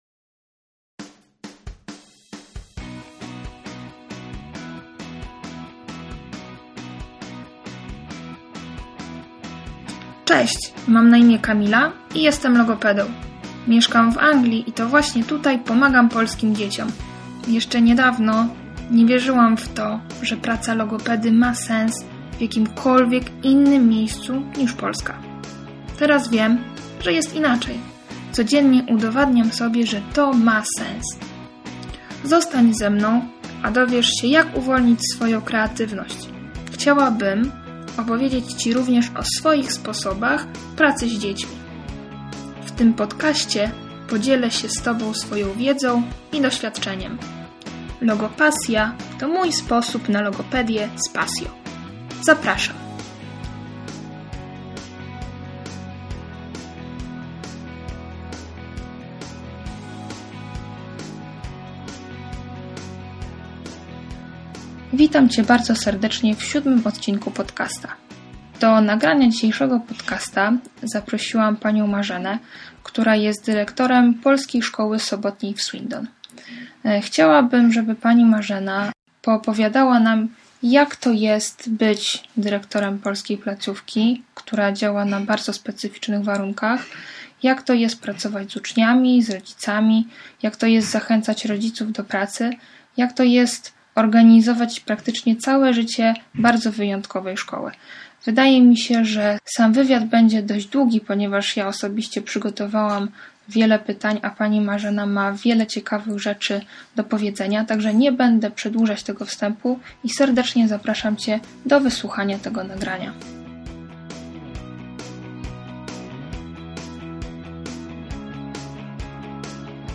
Tym razem jest to Gość „lokalny”, z którym rozmawiałam twarzą w twarz.